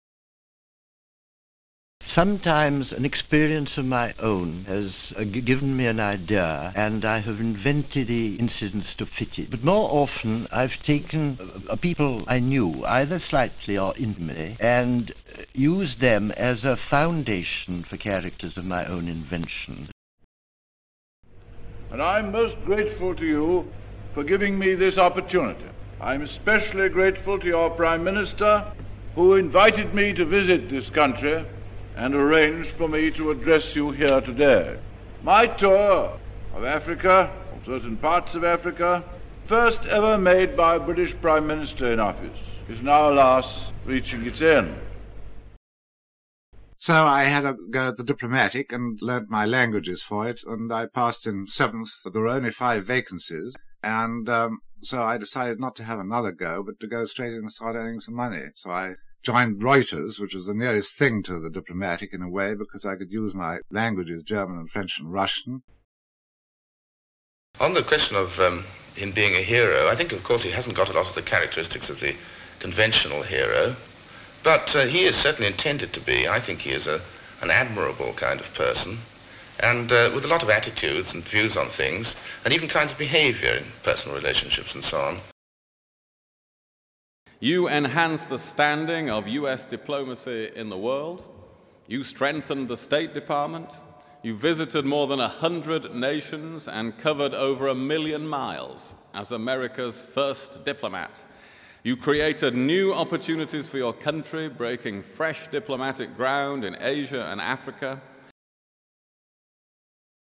Example 4: A selection of RP speakers from the past 125 years:
W. Somerset Maugham (1874-1965), Harold MacMillan (1894-1986), Ian Fleming (1908-1964), Kingsley Amis (1922-1995), William Hague (1961-). This selection does not distinguish between original RP and adopted RP.